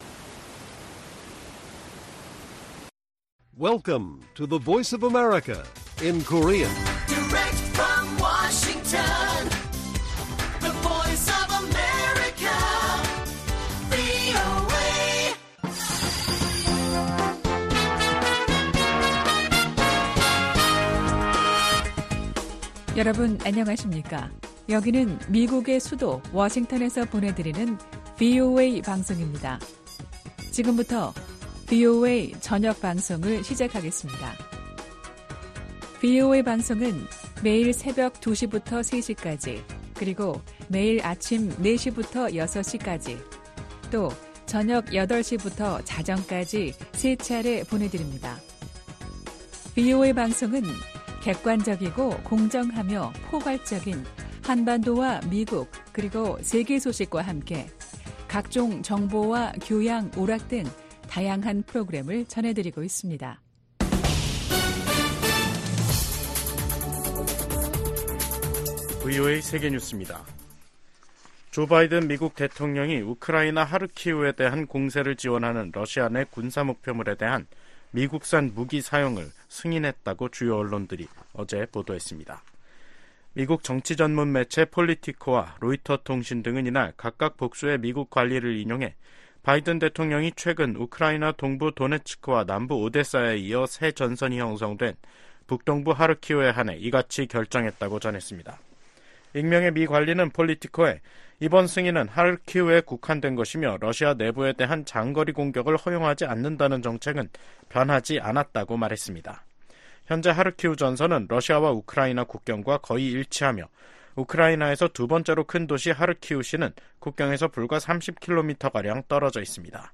VOA 한국어 간판 뉴스 프로그램 '뉴스 투데이', 2024년 5월 31일 1부 방송입니다. 미국 정부는 한반도에 핵무기를 재배치할 계획이 없다고 국무부 대변인이 밝혔습니다. 미 국방부도 현재의 군 태세에 만족한다는 입장을 나타냈습니다. 러시아가 우크라이나 공격에 사용한 탄도미사일이 북한산이라는 사실을 확인하는 미 정보기관의 보고서가 공개됐습니다.